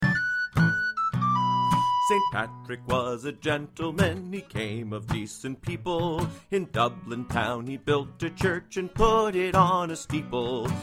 Traditional Irish Song Lyrics and Sound Clip